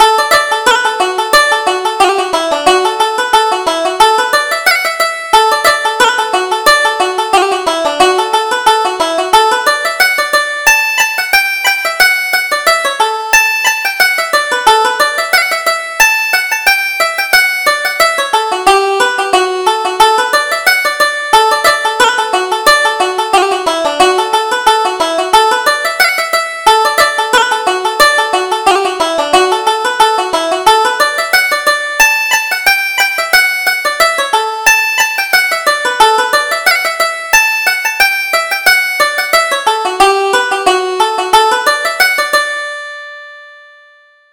Reel: The Magpie's Nest